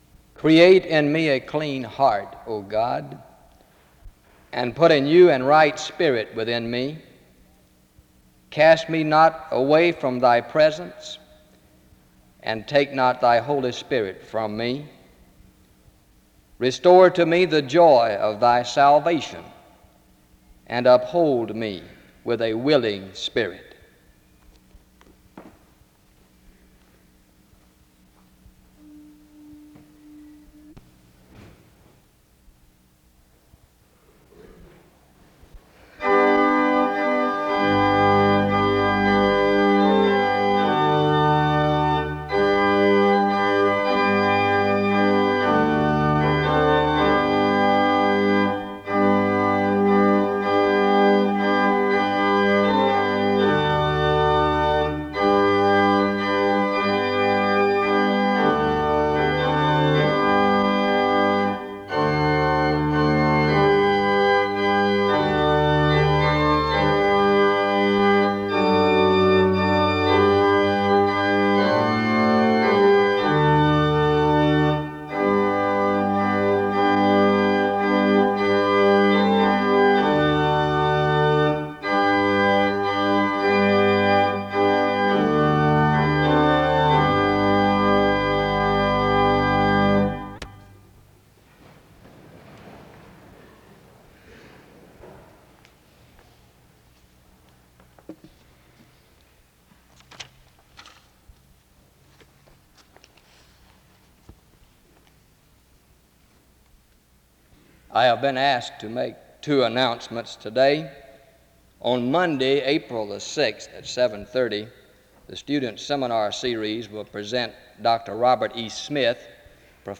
The service opens with scripture reading and music from 0:00-1:30.